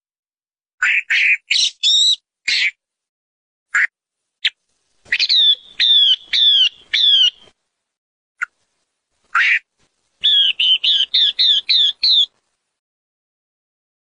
悦耳动听画眉母鸟叫声
画眉，也叫画眉鸟、中国画眉，属于噪鹛科，体重54-54克，体长21-21厘米。中型鸣禽。